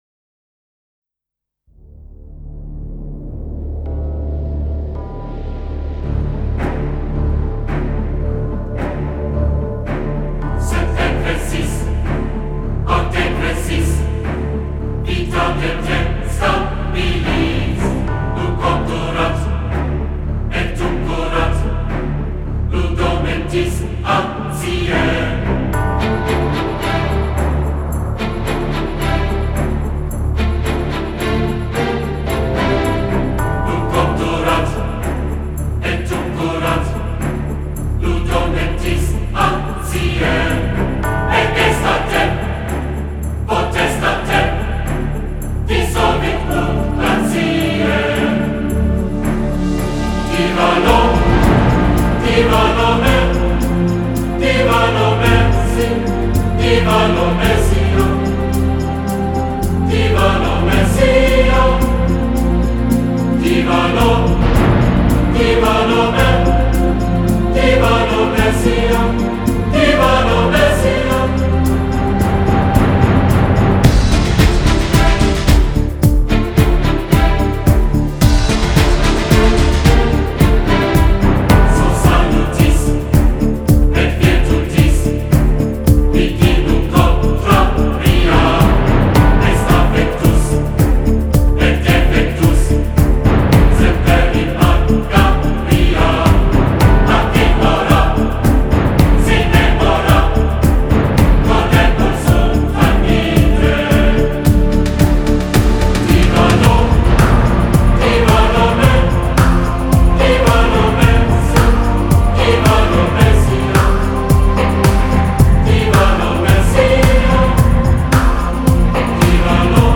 نام یک پروژهٔ موسیقی عصر نو